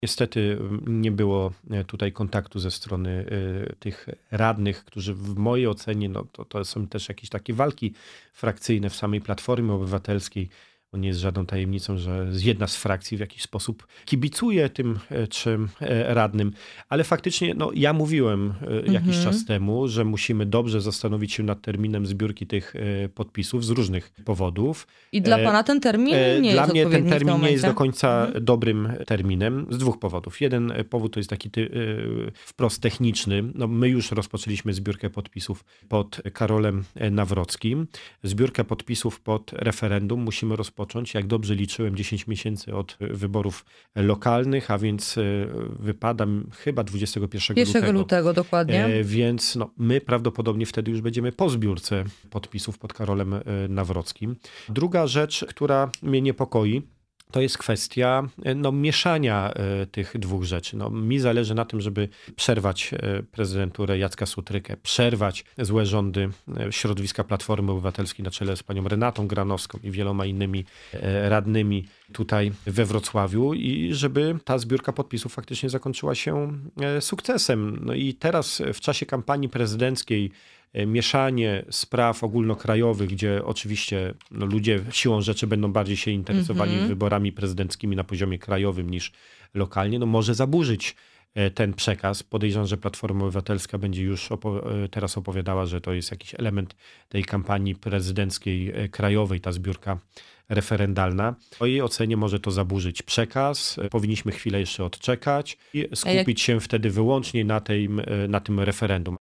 Te tematy poruszyliśmy z „Porannym Gościem” – posłem na Sejm Pawłem Hreniakiem, szefem struktur PiS w okręgu wrocławskim.